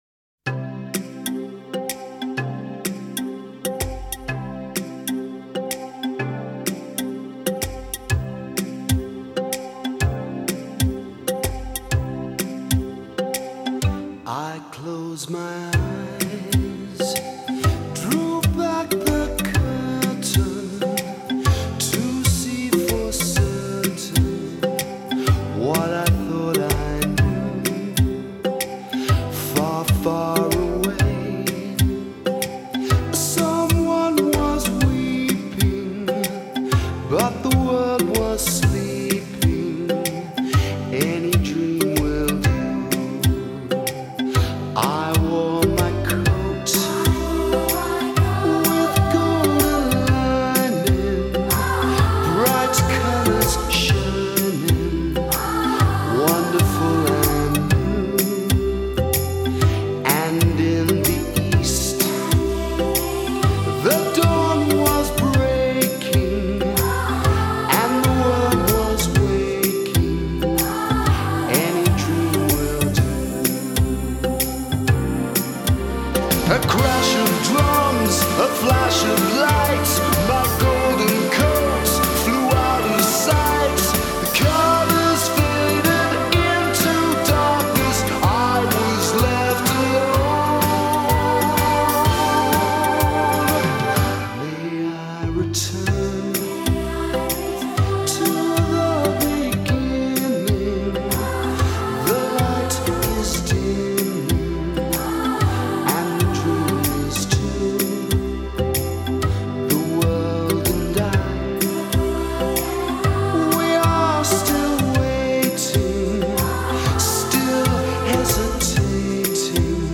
明朗澄澈的录音效果